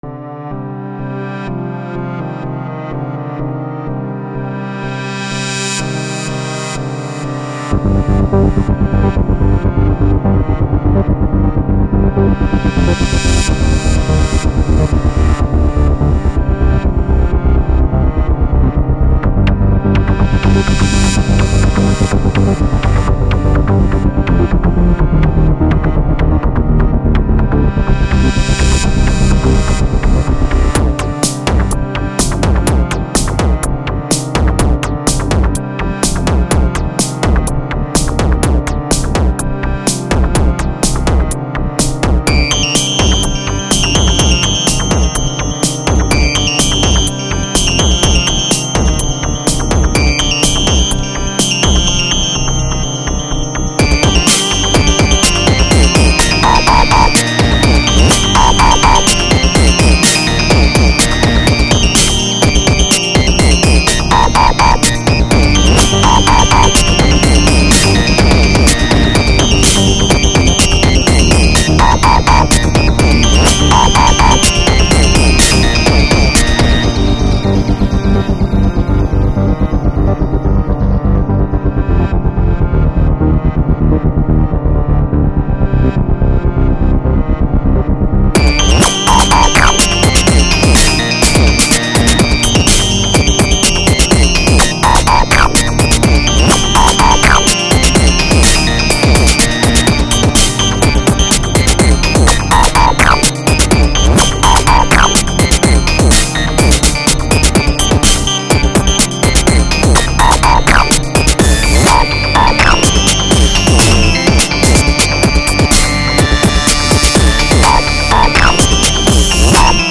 has good electroish sounds and fxs